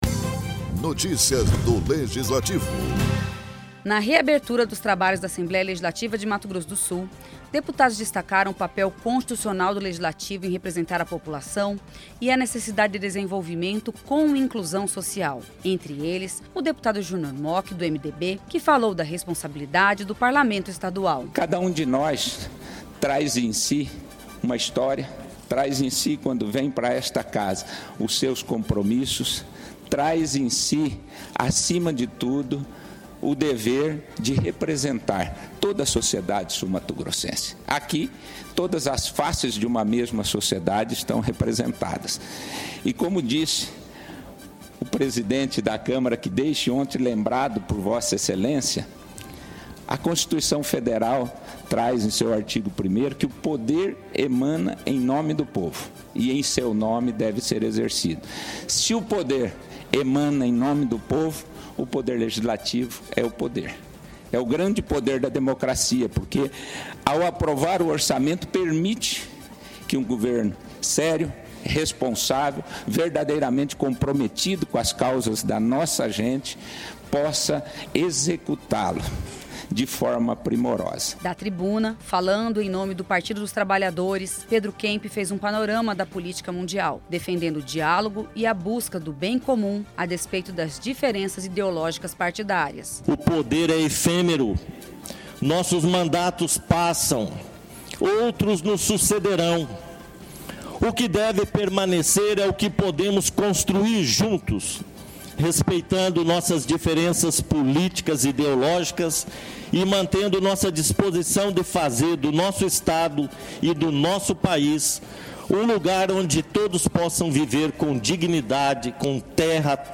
Junior Mochi (MDB), Pedro Kemp (PT) e Paulo Duarte (PSB) ocuparam a tribuna da Casa de Leis para falarem pela liderança dos blocos e bancadas.